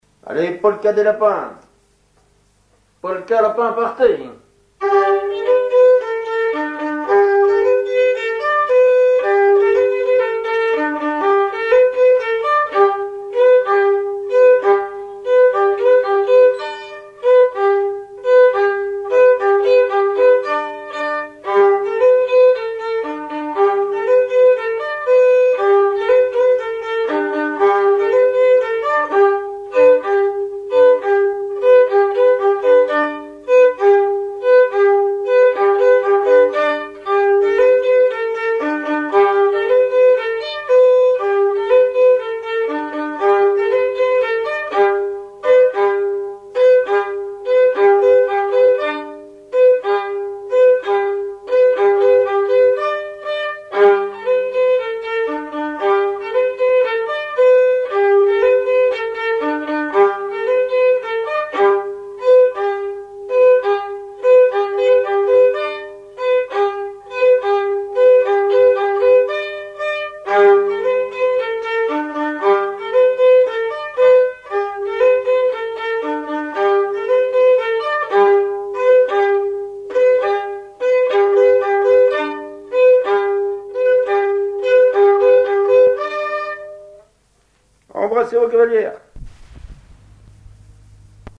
danse : polka lapin
Auto-enregistrement
Pièce musicale inédite